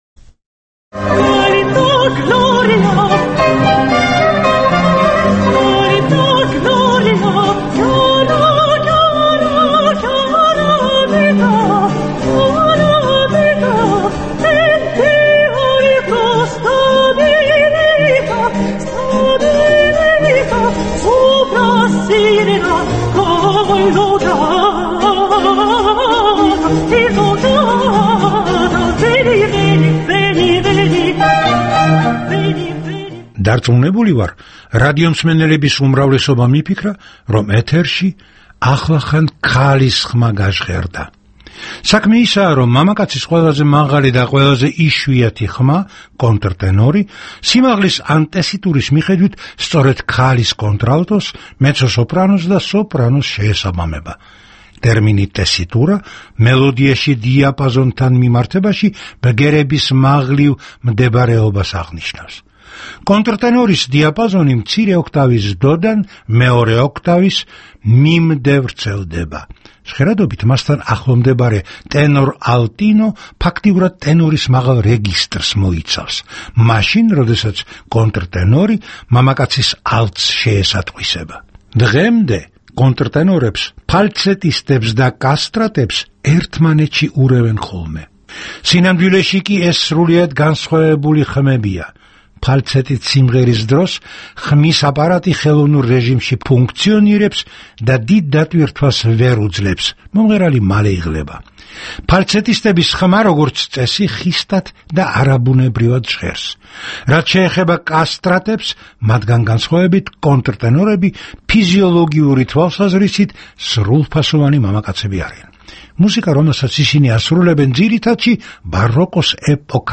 მამაკაცის ყველაზე მაღალი და ყველაზე იშვიათი ხმა, კონტრტენორი, სიმაღლის ან ტესიტურის მიხედვით, სწორედ ქალის კონტრალტოს, მეცო-სოპრანოს და სოპრანოს შეესაბამება.